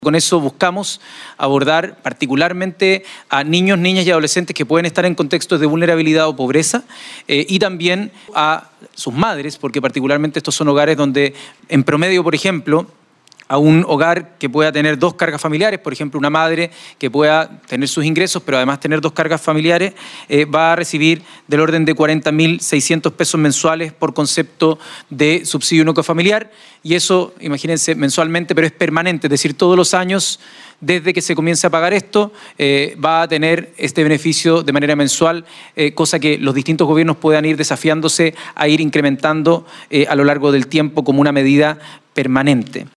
Escucha aquí la entrevista que el seremi de Gobierno del Biobío, Eduardo Vivanco, concedió a Radio UdeC sobre el paquete de medidas: